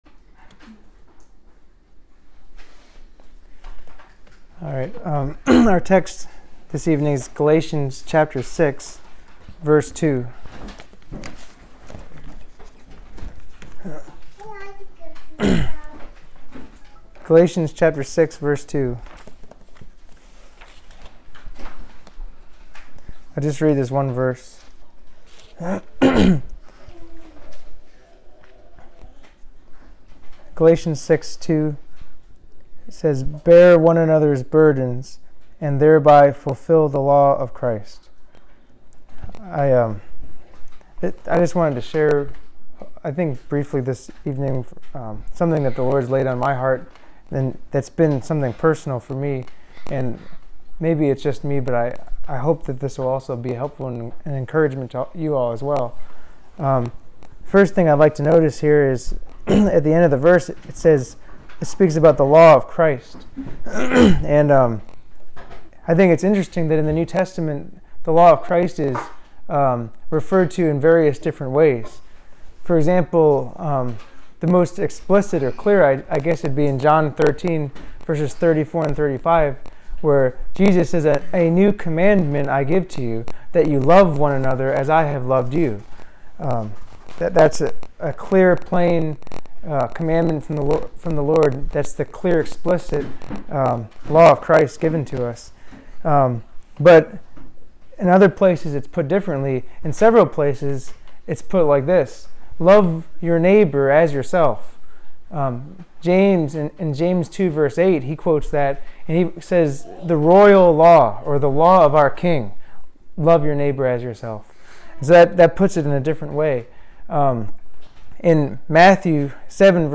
Devotional message given at Saturday night prayer meeting